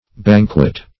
Banquet \Ban"quet\, v. i.